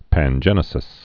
(păn-jĕnĭ-sĭs)